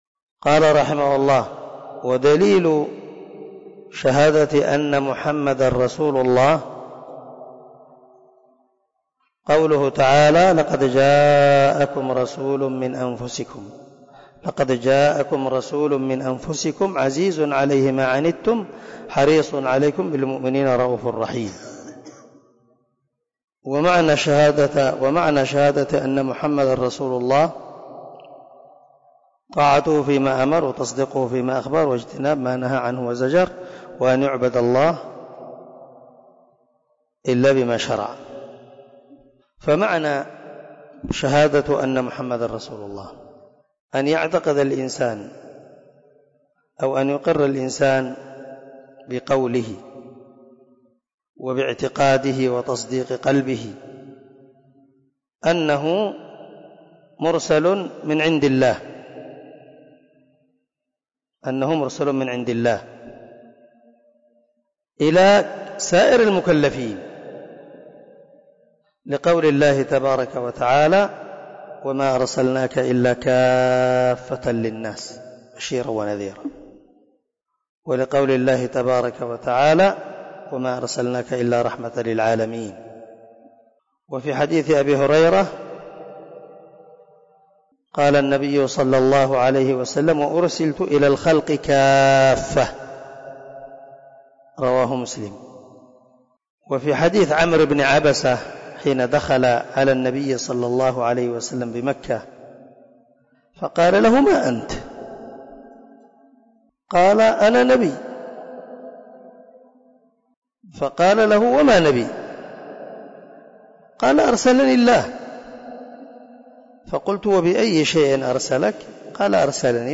🔊 الدرس 24 من شرح الأصول الثلاثة